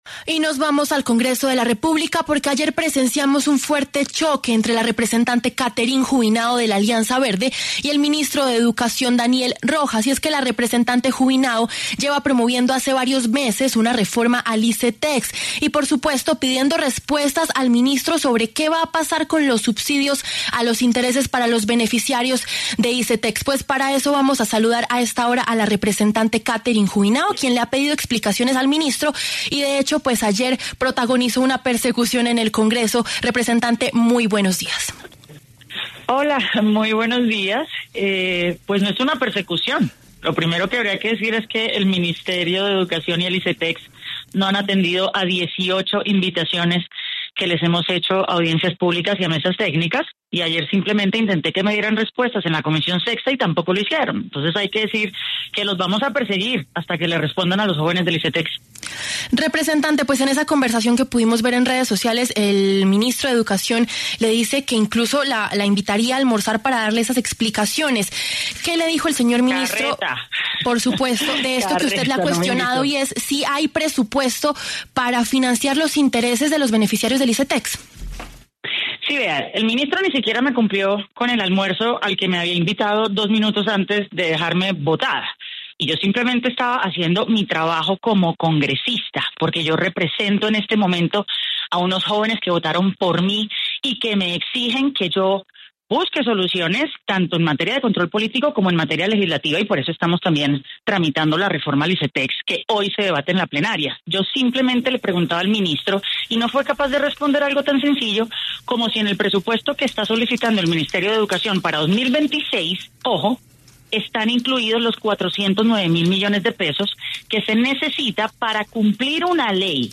Los representantes de la Alianza Verde, Catherine Juvinao y Jaime Raúl Salamanca, quienes se enfrentaron en la Comisión Sexta del Senado, pasaron por los micrófonos de La W.